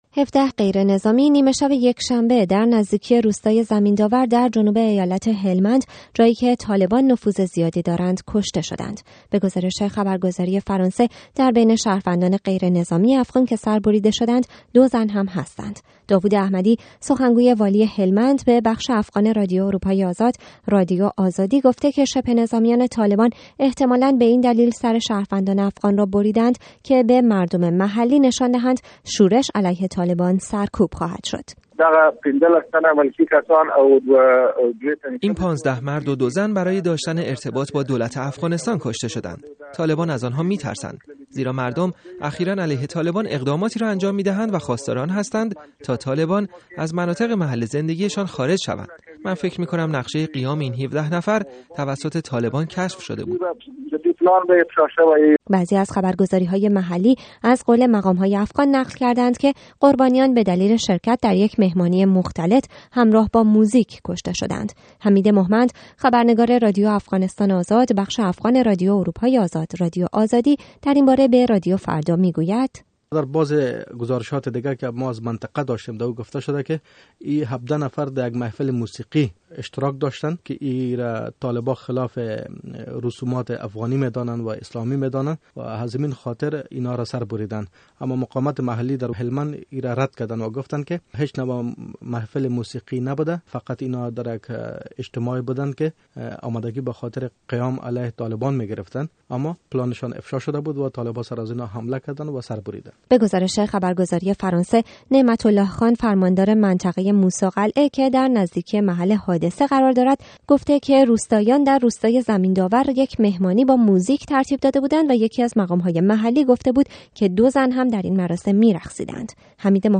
گزارش رادیویی: «شورشيان طالبان» ۱۷ شهروند افغانستان را سر بريدند